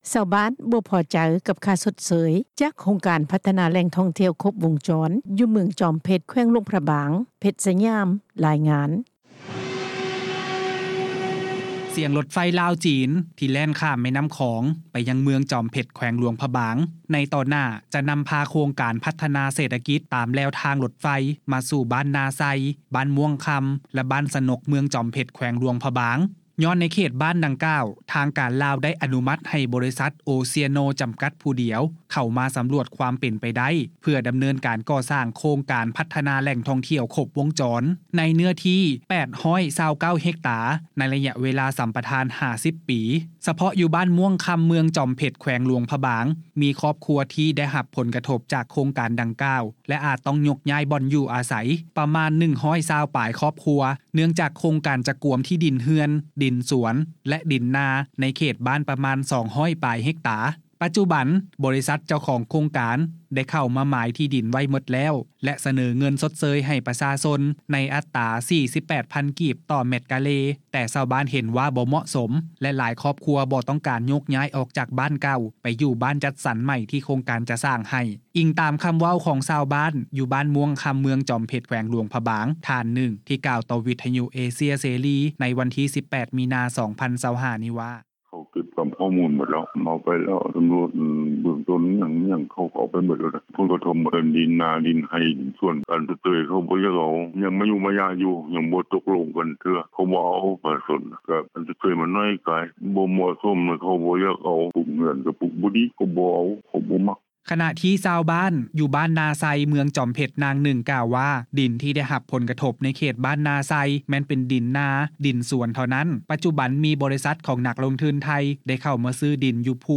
ສຽງ ລົດໄຟລາວ-ຈີນ ທີ່ແລ່ນຂ້າມແມ່ນ້ຳຂອງ ໄປຍັງເມືອງຈອມເພັດ ແຂວງຫຼວງພຣະບາງ ໃນຕໍ່ໜ້າ ຈະນຳພາໂຄງການພັດທະນາ ເສດຖະກິດ ຕາມແລວທາງລົດໄຟ ມາສູ່ບ້ານນາໄຊ ບ້ານມ່ວງຄຳ ແລະບ້ານສະນົກ ເມືອງຈອມເພັດ ແຂວງຫຼວງພຣະບາງ ຍ້ອນໃນເຂດບ້ານດັ່ງກ່າວ ທາງການລາວ ໄດ້ອະນຸມັດ ໃຫ້ບໍລິສັດ ໂອເຊຍໂນ ຈໍາກັດຜູ້ດຽວ ເຂົ້າມາສຳຫຼວດ ຄວາມເປັນໄປໄດ້ ເພື່ອດຳເນີນການກໍ່ສ້າງ ໂຄງການພັດທະນາ ແຫຼ່ງທ່ອງທ່ຽວ ຄົບວົງຈອນ ໃນເນື້ອທີ່ 829 ເຮັກຕ້າ ໄລຍະເວລ າສຳປະທານ 50 ປີ.